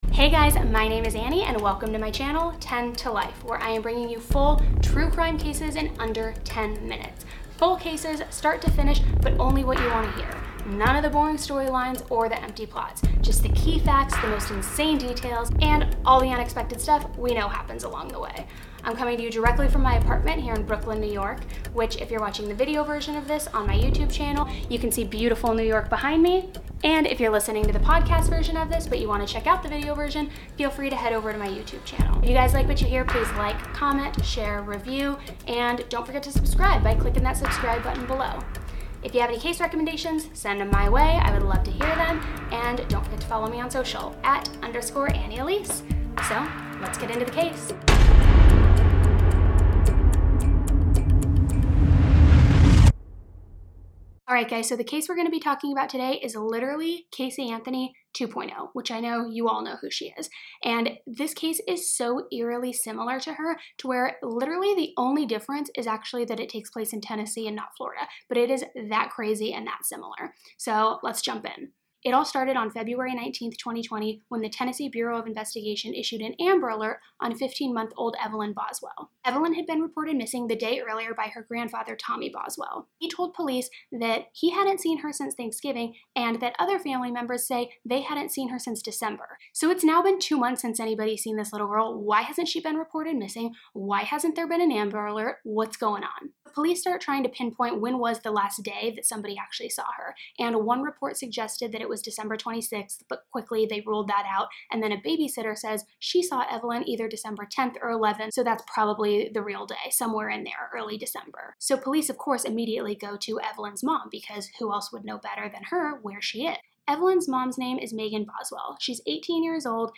in an engaging, conversational way